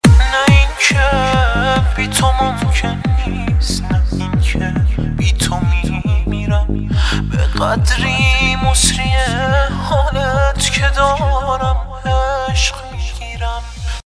رینگتون با کلام